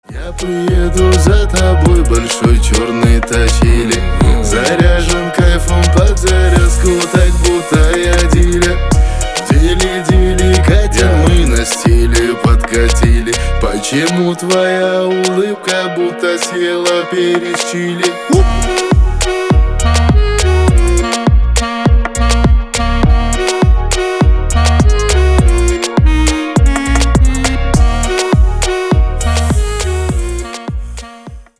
бесплатный рингтон в виде самого яркого фрагмента из песни
Рэп и Хип Хоп